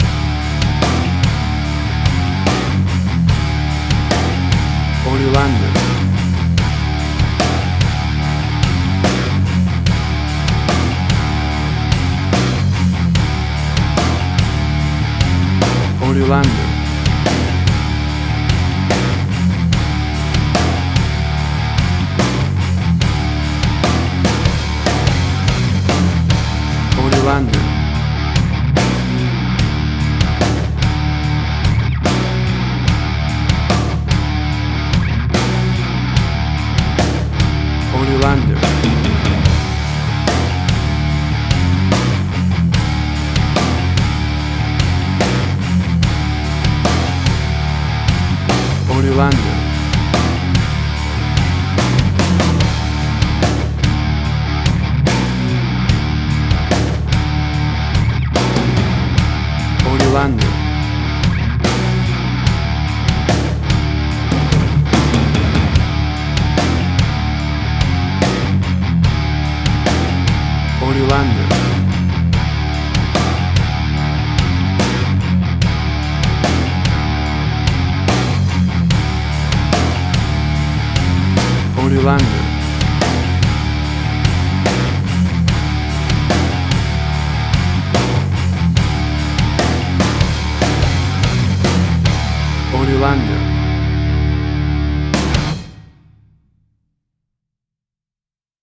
Hard Rock
Heavy Metal
Tempo (BPM): 73